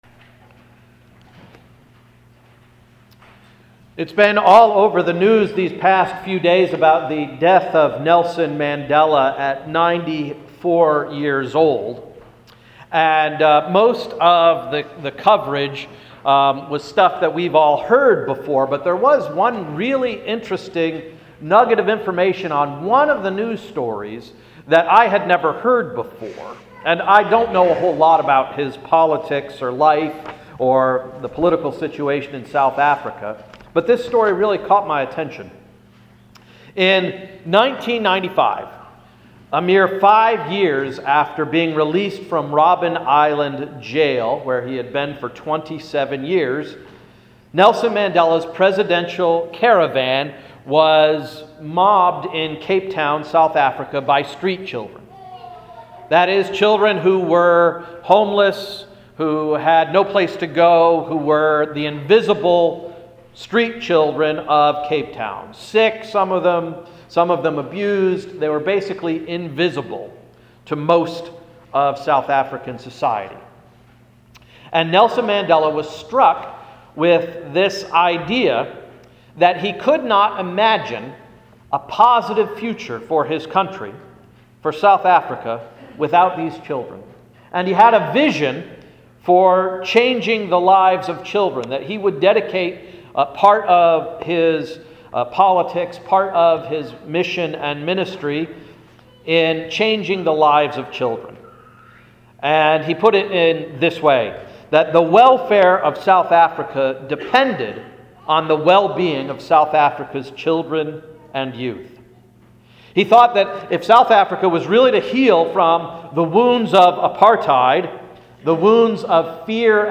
Sermon of December 8, 2013–“The Growing Season”